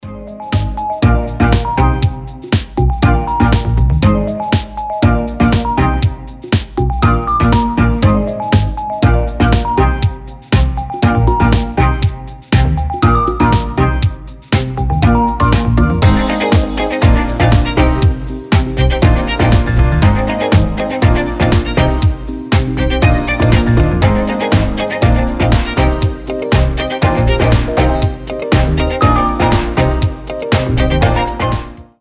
• Качество: 320, Stereo
мелодичные
веселые
без слов
скрипка
Хорошая мелодия с пузырями и скрипочкой